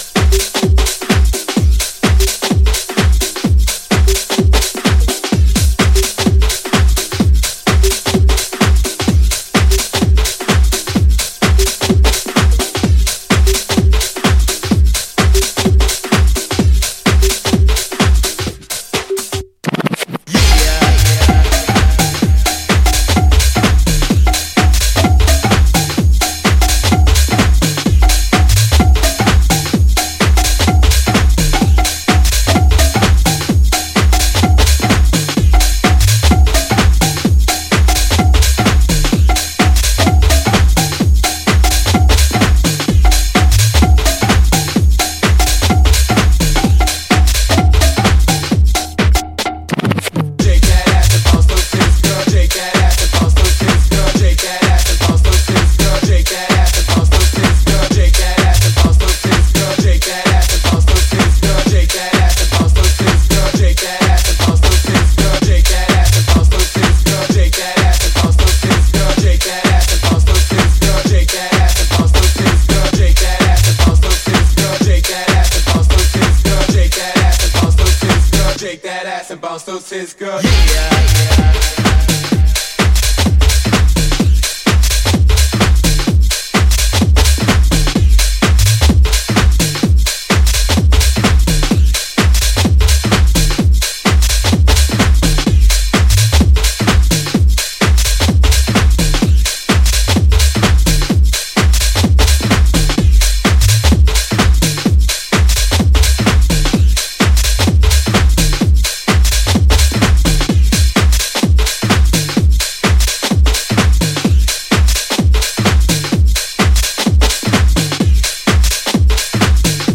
UKG jacker